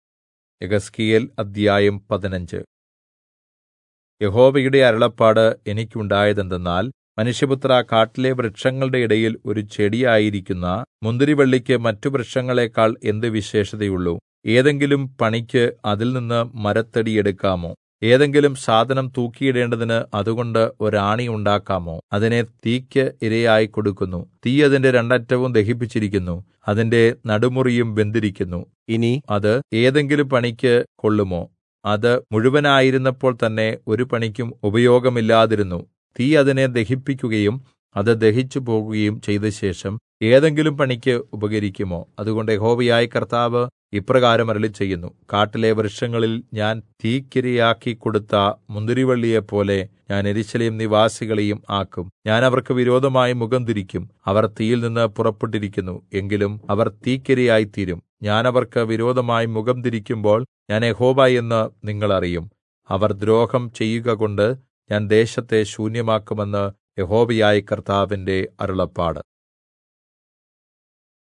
Malayalam Audio Bible - Ezekiel 23 in Irvml bible version